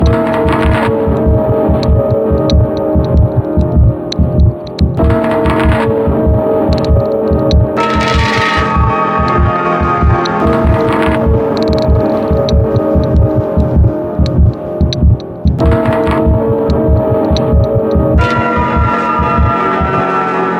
Category 😂 Memes